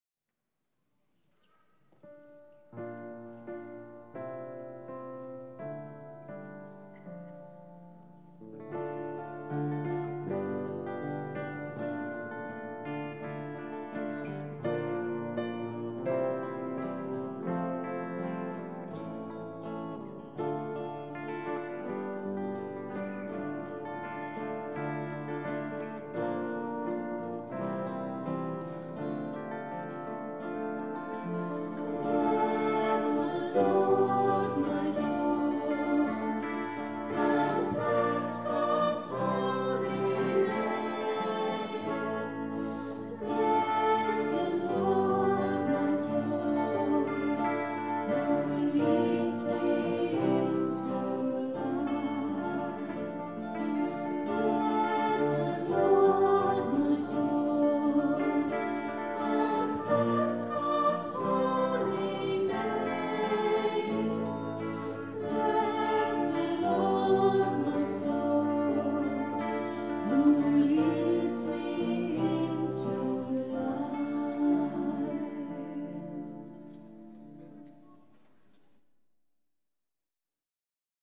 Recorded on a Zoom H4 digital stereo recorder at 10am Mass Sunday 7th November 2010.